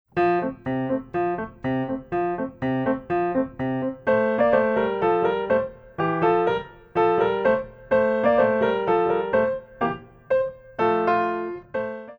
By Pianist & Ballet Accompanist
Piano selections include:
Frappé